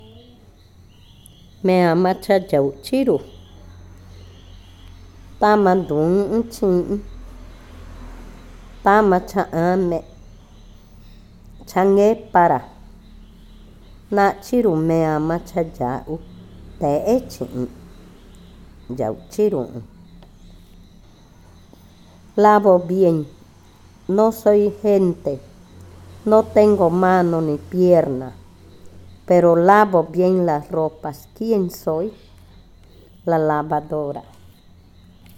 Adivinanza 15. La lavadora
Cushillococha